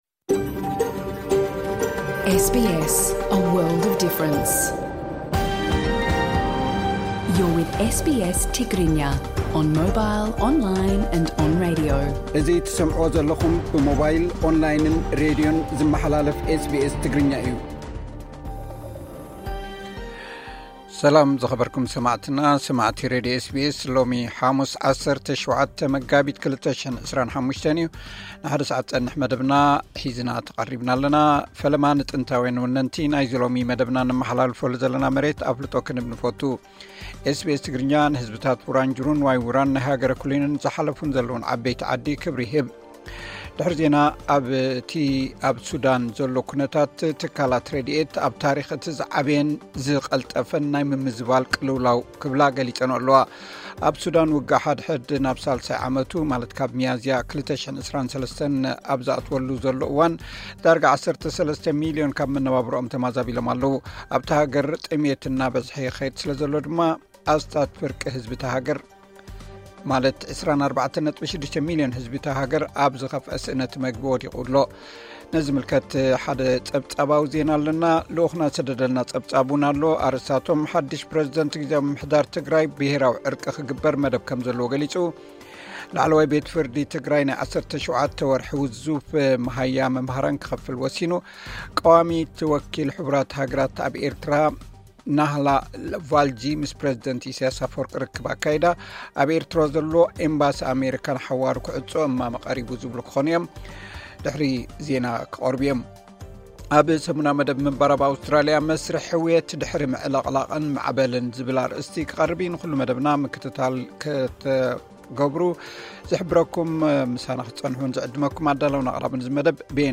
ቀጥታ ምሉእ ትሕዝቶ ኤስ ቢ ኤስ ትግርኛ (17 ሚያዝያ 2025)
ነዚ ዝምልከት ሓደ ጸብጻባዊ ዜና ኣለና።